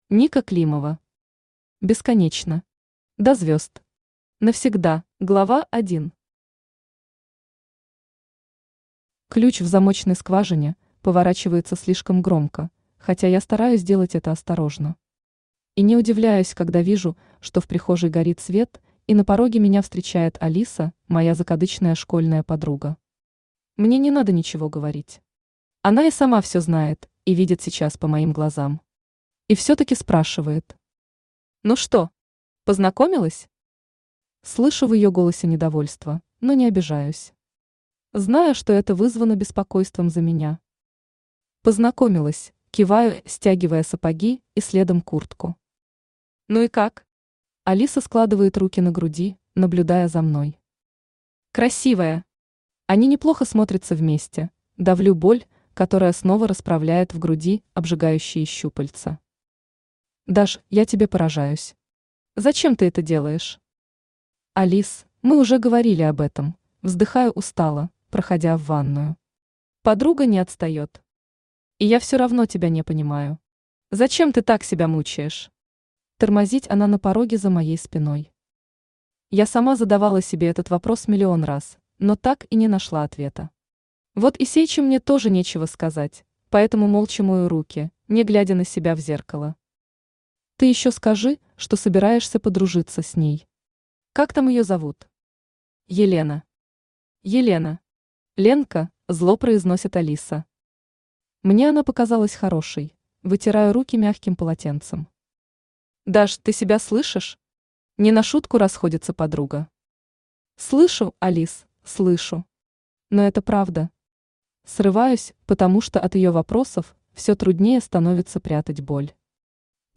Аудиокнига Бесконечно. До звезд. Навсегда | Библиотека аудиокниг